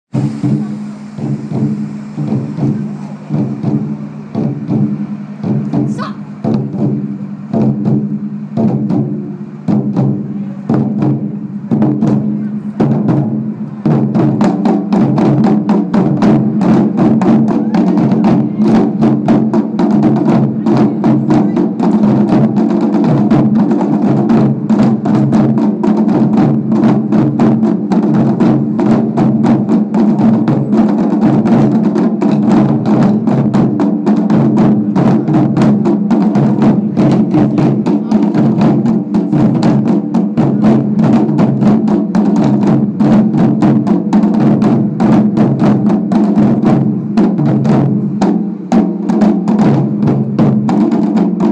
Drumming
Norwich Taiko Drummers help kick off the Magdalen-Augustine Celebration 2012